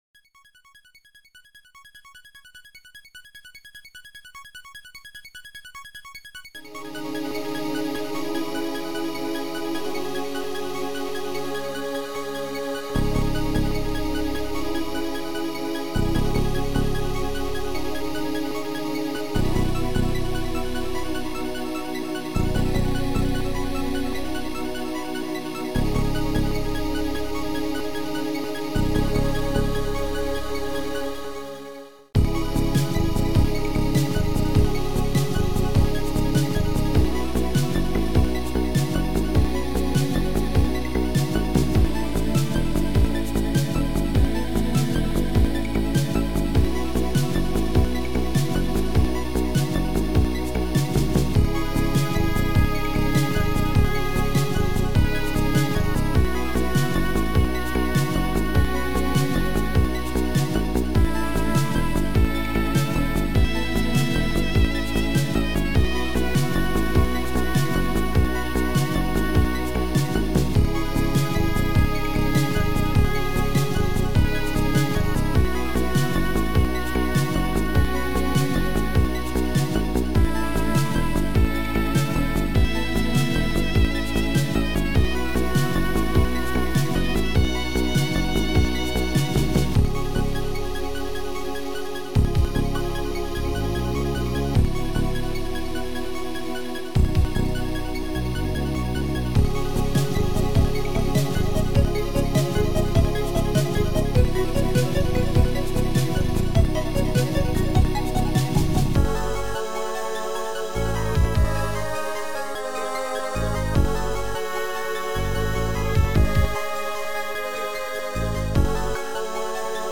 Sound Format: Noisetracker/Protracker
Sound Style: Atmospherical / Mellow